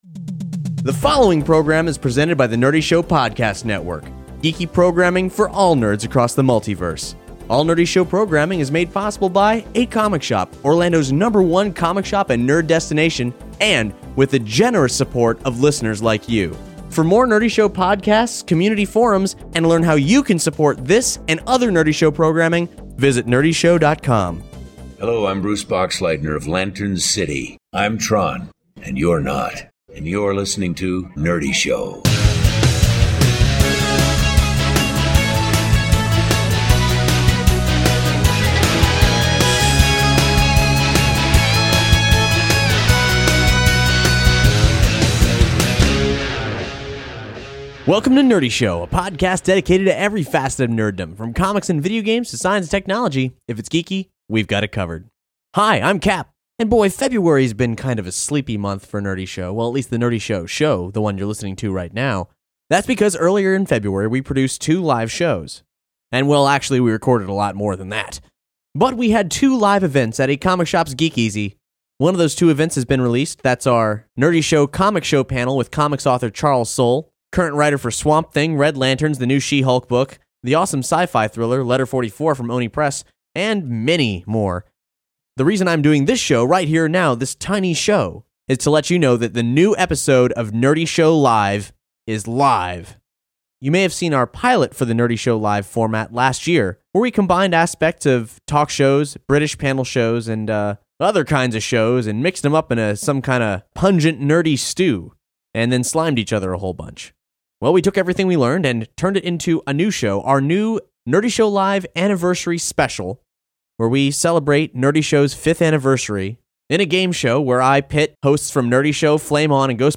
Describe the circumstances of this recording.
Our live show is back with a vengeance!